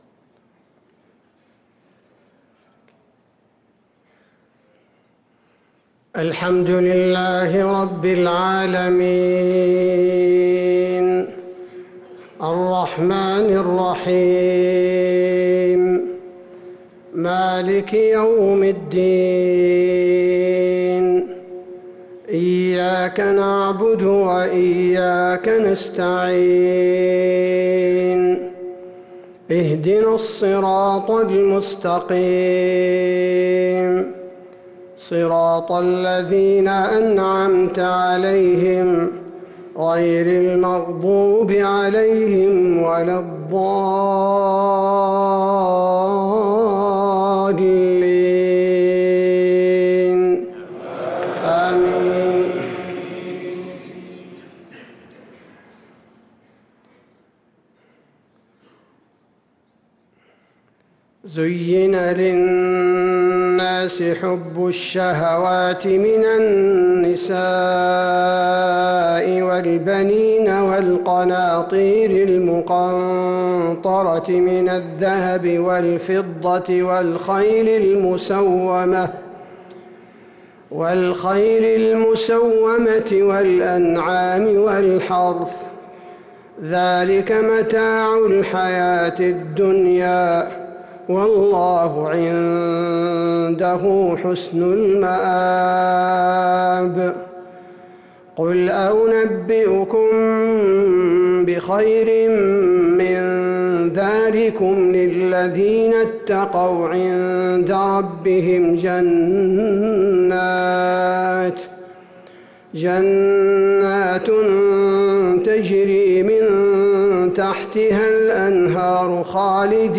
صلاة المغرب للقارئ عبدالباري الثبيتي 27 شوال 1444 هـ
تِلَاوَات الْحَرَمَيْن .